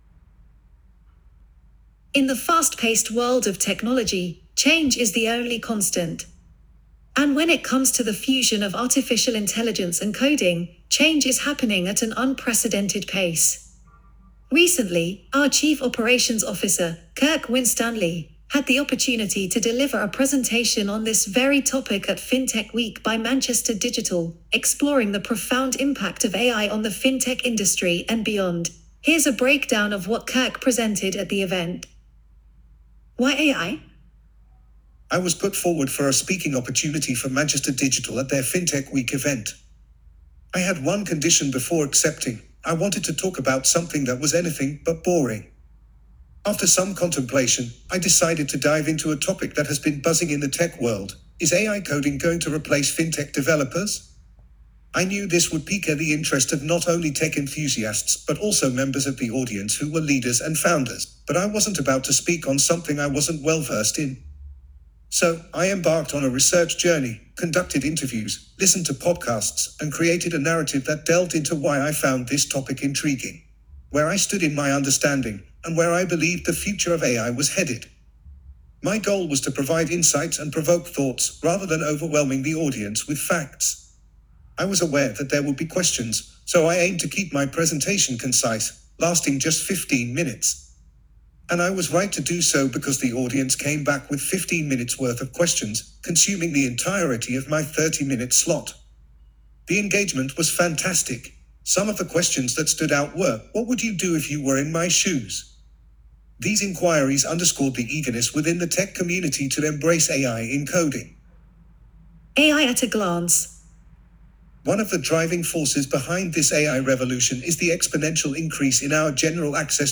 here to listen to the blog via the power of AI.